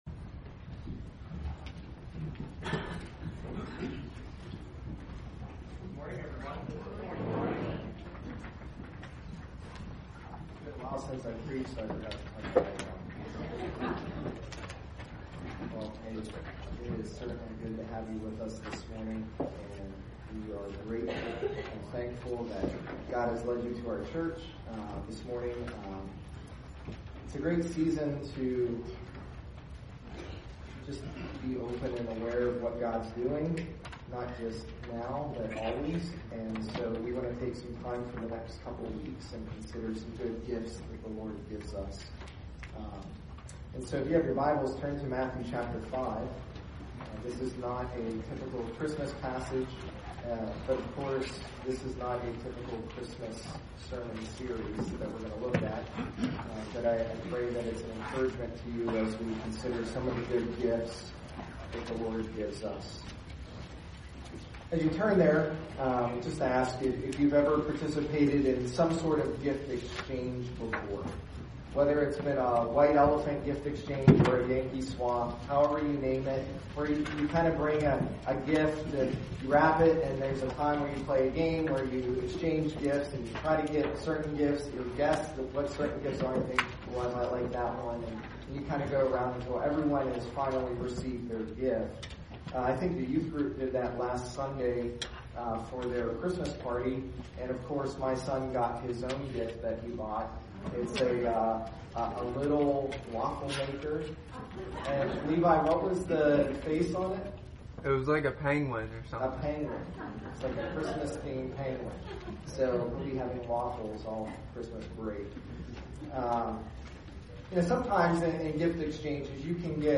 Sermons | North Annville Bible Church